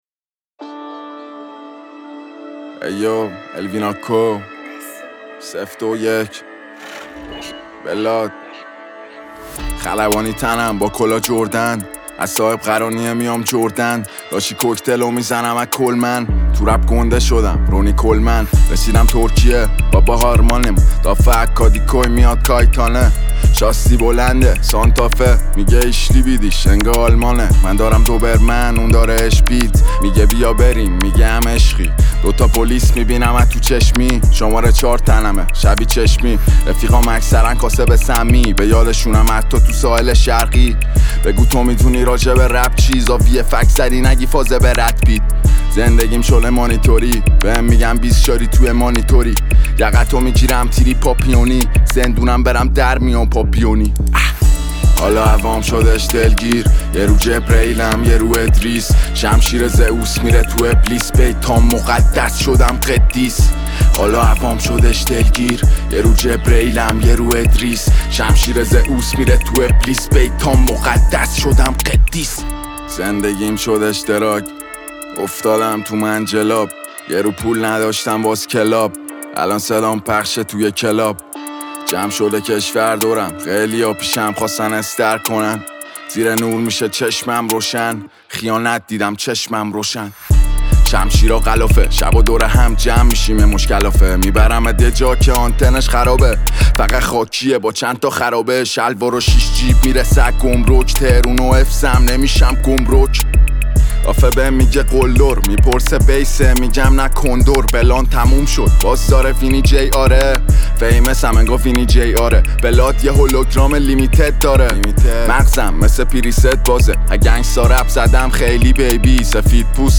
رپ فارسی